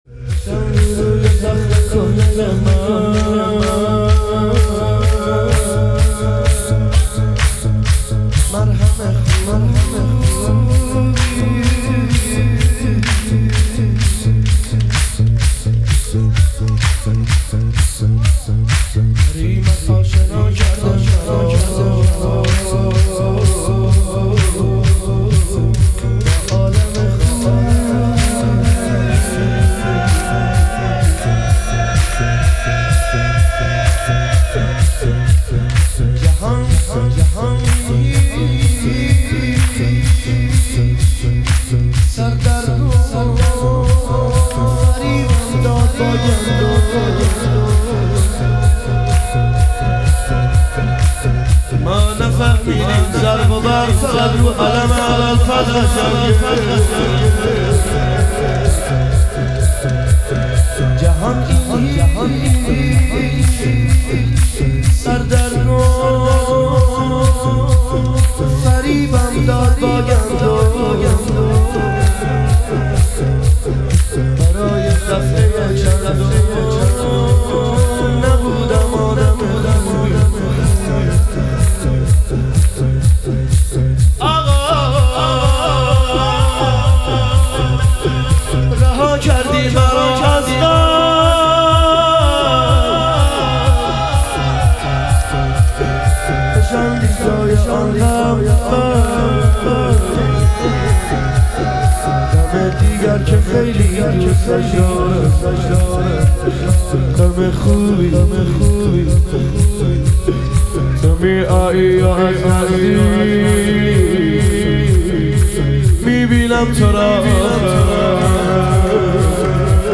لطمه زنی
شب شهادت امام صادق علیه السلام